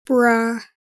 Rebellious Female Teen Says Bruh
Category 🗣 Voices